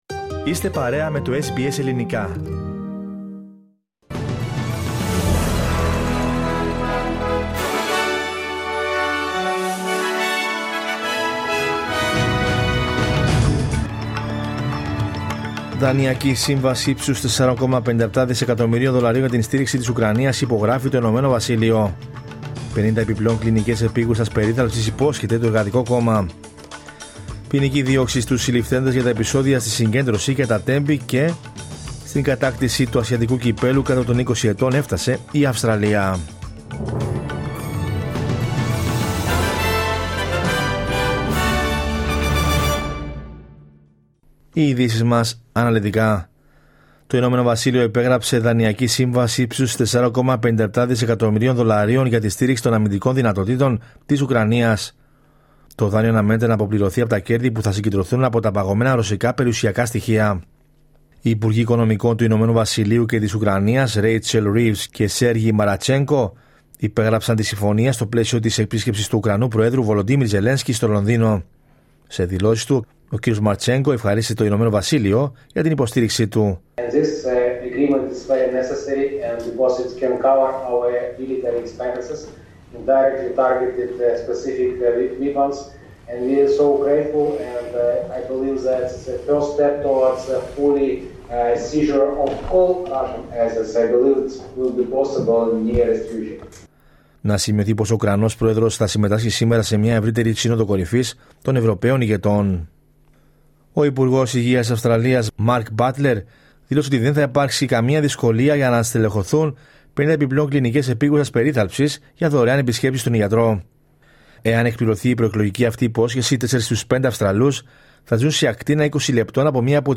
Δελτίο Ειδήσεων Κυριακή 2 Μαρτίου 2025